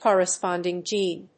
corresponding+gene.mp3